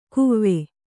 ♪ kuvve